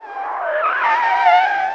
tyre_skid_01.wav